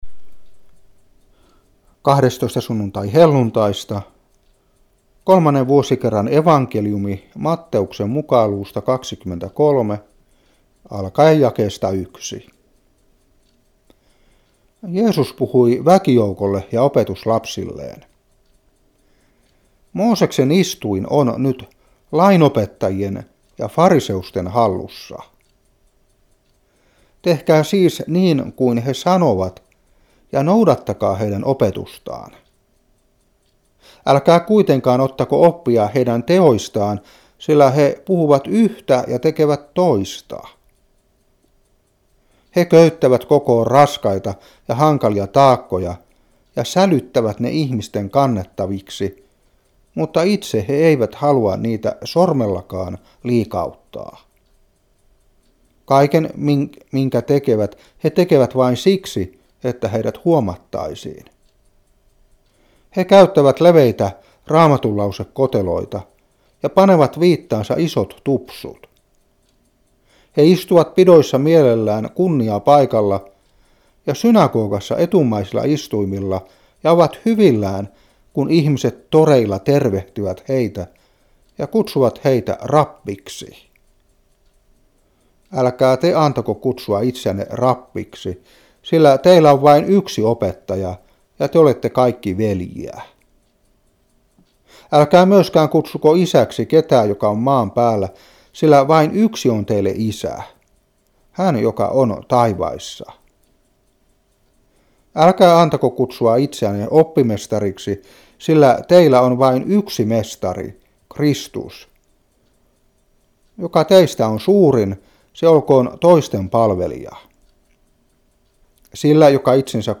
Saarna 2012-8.